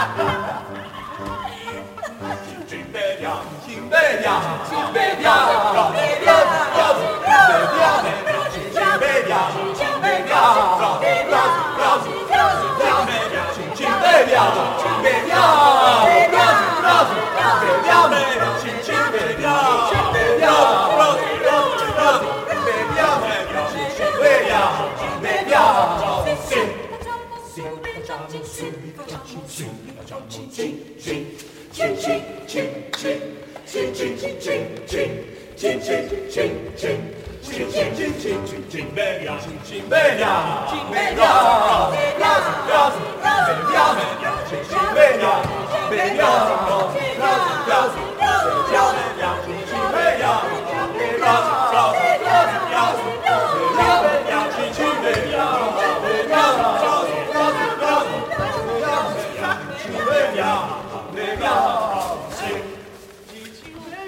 17 coro
17-coro.mp3